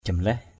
/ʥʌm-lɛh/ (d.) cây chùm lé.